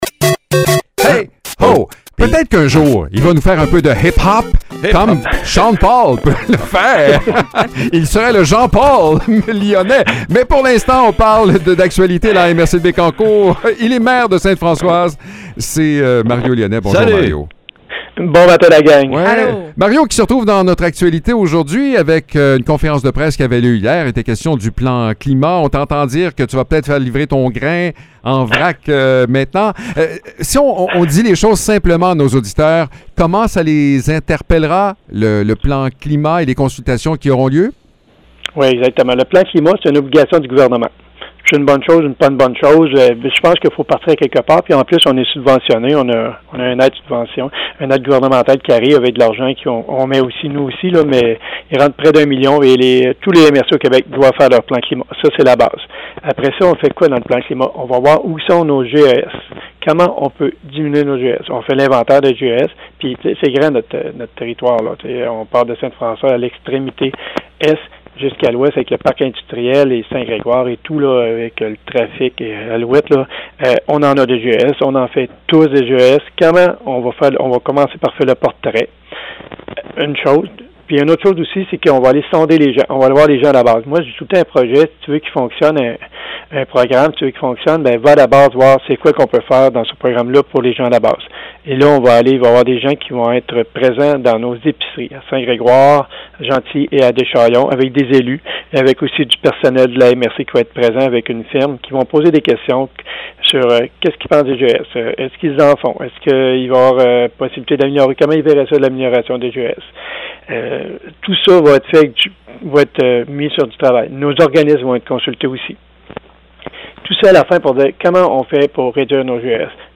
Mario Lyonnais, maire du village de Sainte-Françoise et préfet de la MRC de Bécancour, nous parle du climat… et il a même trouvé une façon bien personnelle de faire une différence.